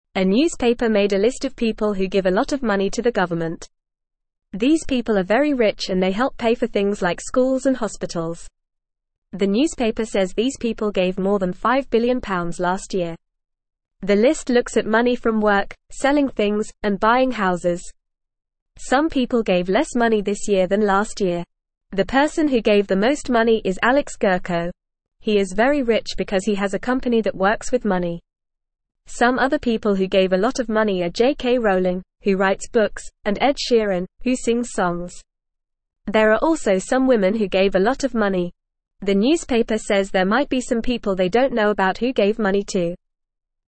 Fast
English-Newsroom-Beginner-FAST-Reading-Rich-People-Give-Lots-of-Money-to-Help.mp3